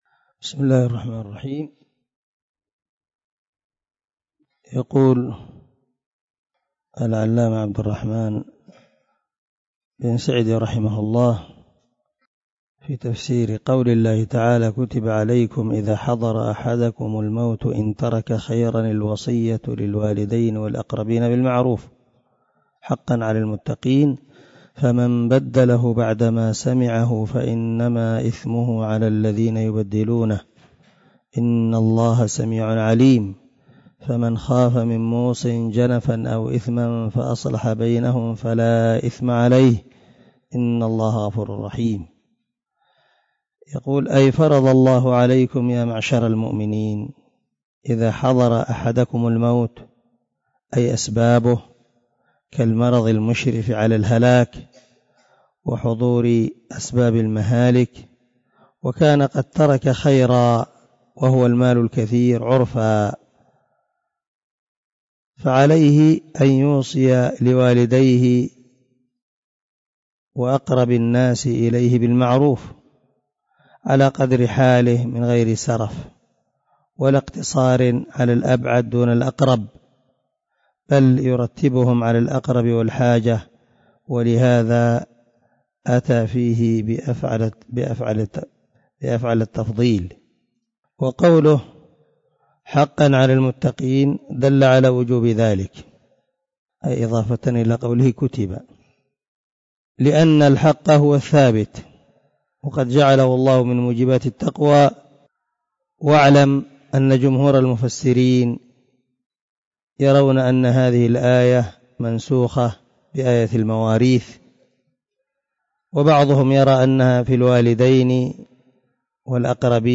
081الدرس 71 تابع تفسير آية ( 180 – 182 ) من سورة البقرة من تفسير القران الكريم مع قراءة لتفسير السعدي
دار الحديث- المَحاوِلة- الصبيحة.